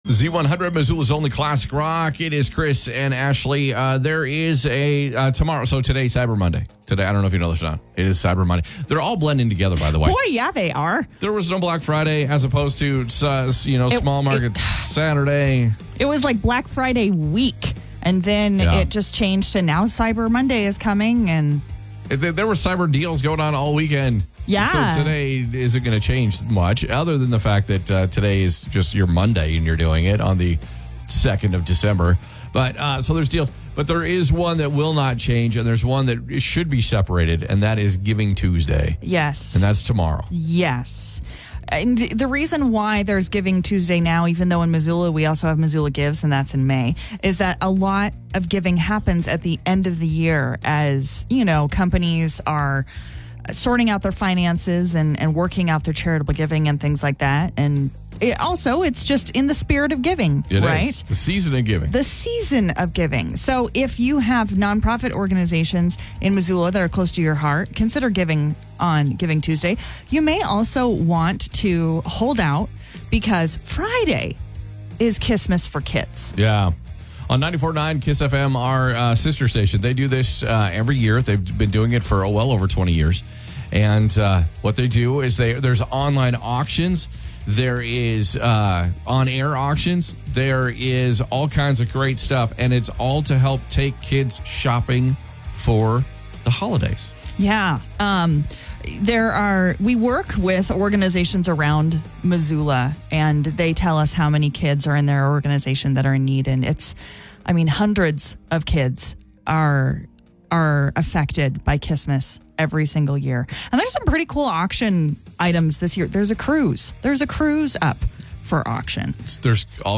is continuing the tradition of being a local, entertaining morning radio show.